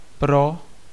Ääntäminen
IPA : /fɔɹ/ US : IPA : [fɔɹ] US